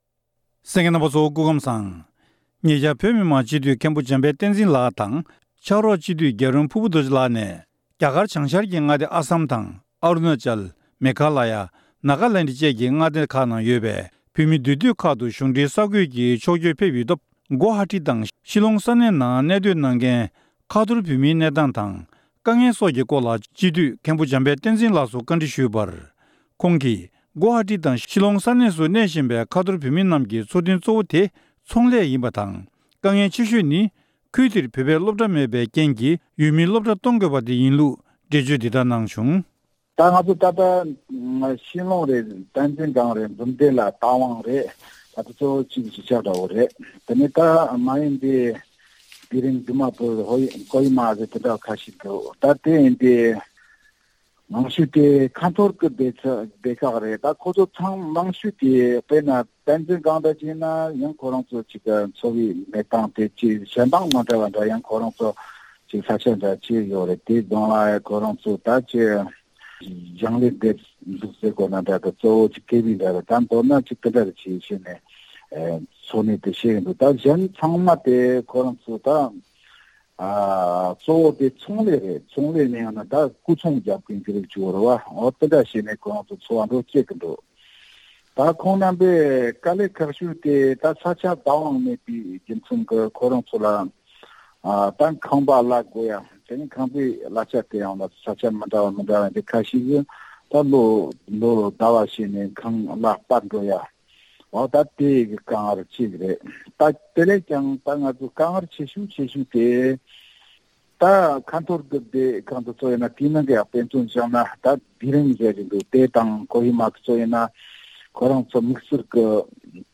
བཀའ་འདྲི་ཞུས་ནས་ ཕྱོགས་སྒྲིགས་ཞུས་པ་ཞིག་གསན་རོགས་གནང་།།